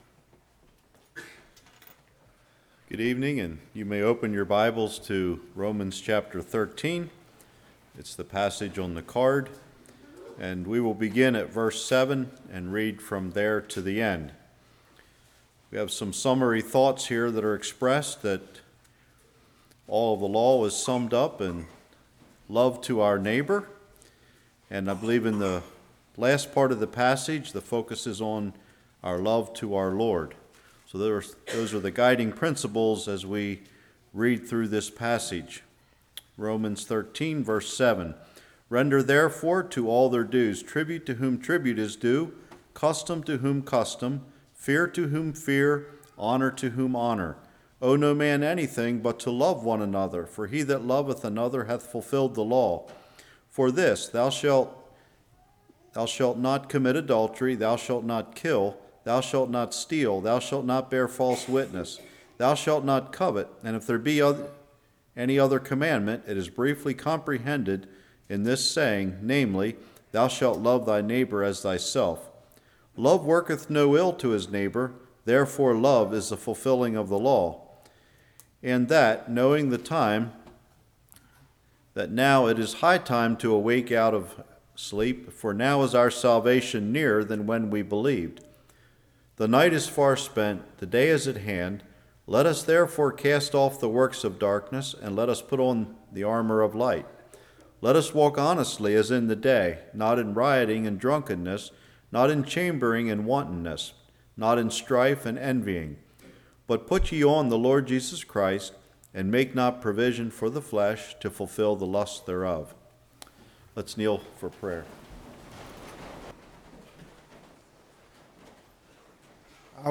Romans 13:7-14 Service Type: Revival What is Biblical Honor?